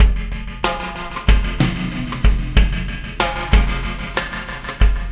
Pulsar Beat 12.wav